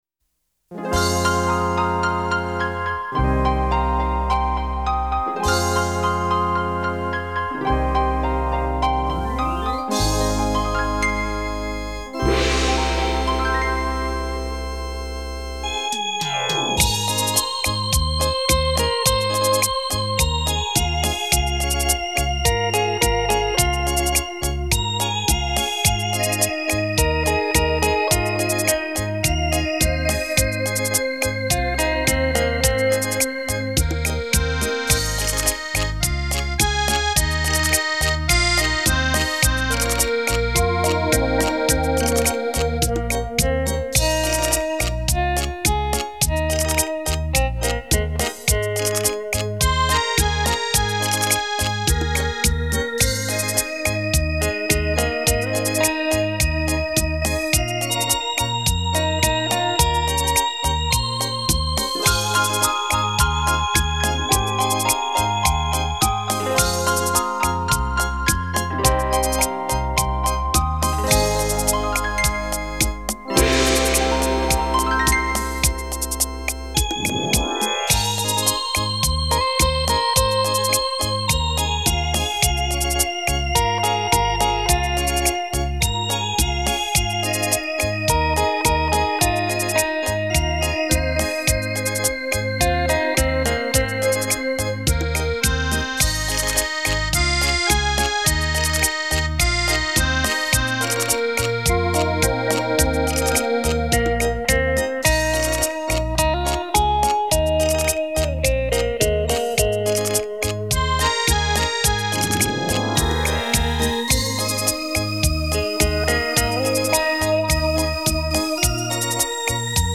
,很怀念的电子琴 谢谢分享
国语老歌